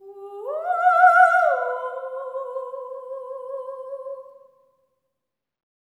OPERATIC06-R.wav